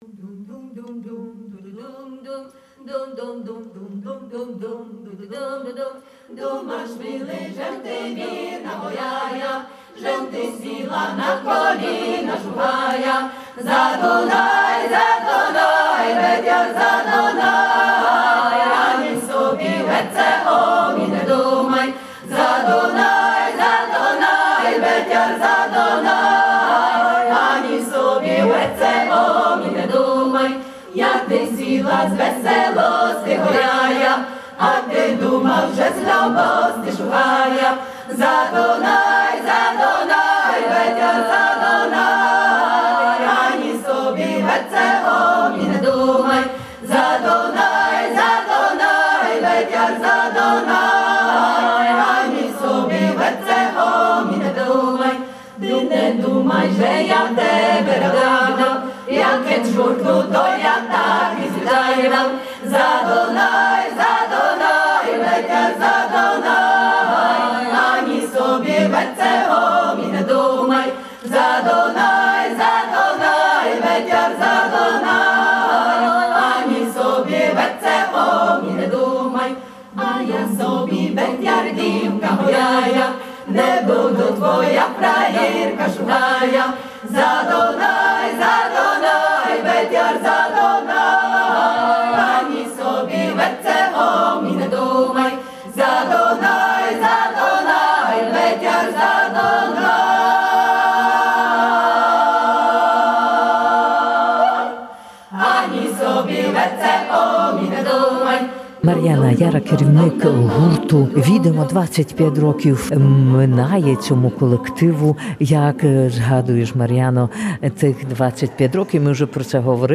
Художній колектив Відимо я Санока відсвяткував 25 річчя існування. Святковий концерт відбувася в саноцькому державному інституті. Були спомини, сльози, квіти і звичайно пісня.